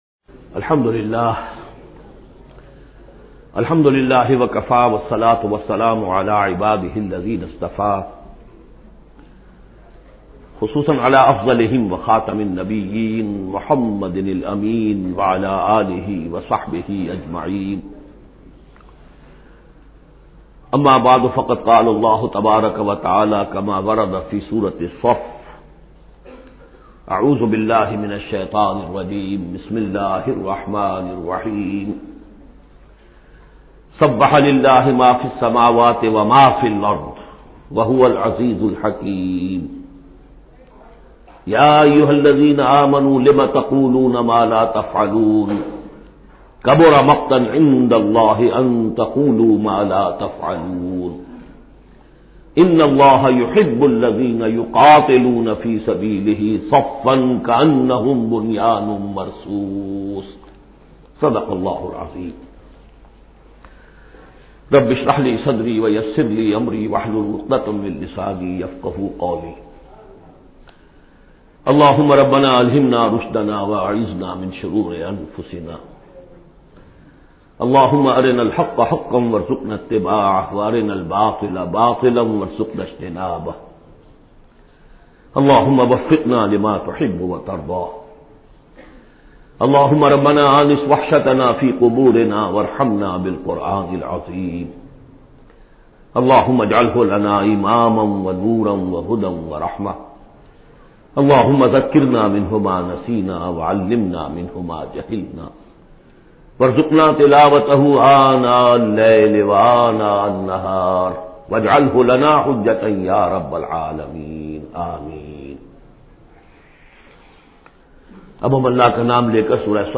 Surah As Saff Audio Tafseer